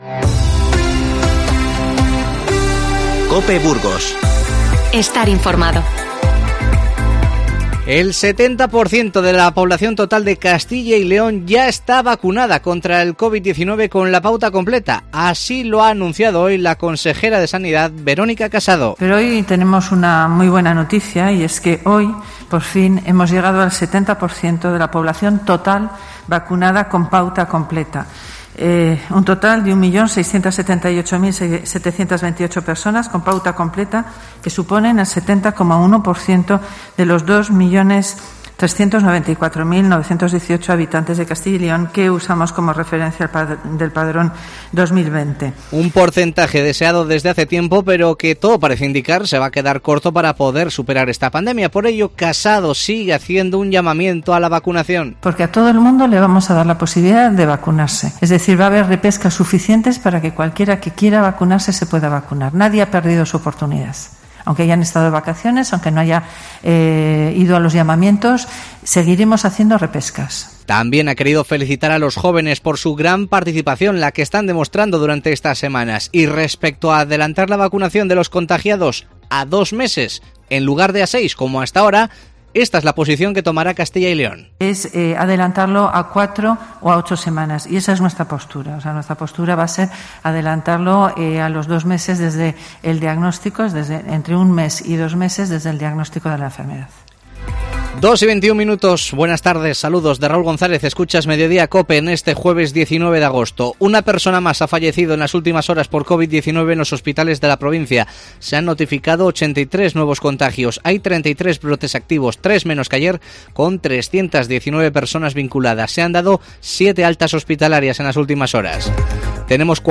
Informativo 19-08-21